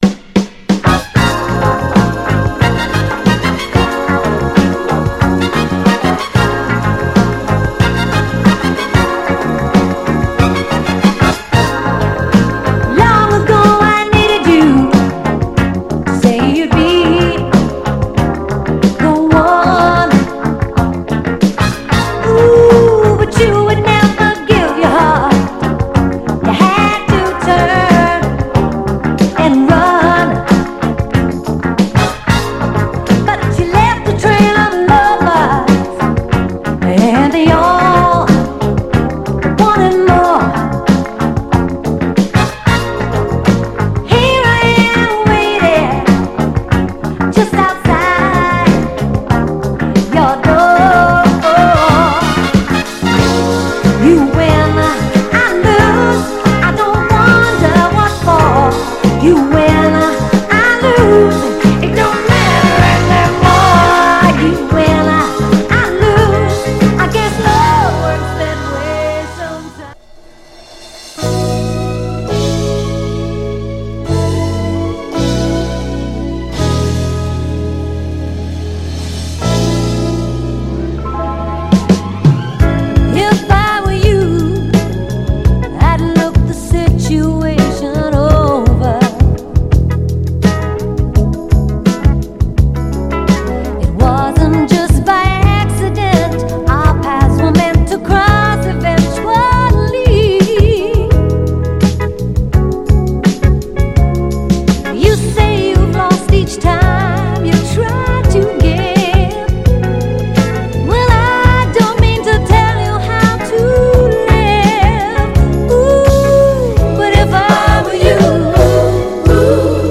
メロウ・ステッパーな
※試聴音源は実際にお送りする商品から録音したものです※